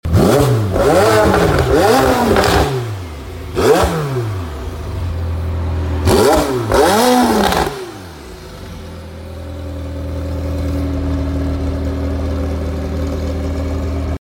Mclaren 720S Coupe Novitec Sound Effects Free Download
Mclaren 720S Coupe Novitec Tune Stage 2 800HP Full Carbon